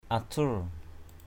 /a-tʰur/